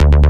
FK092BASS1-R.wav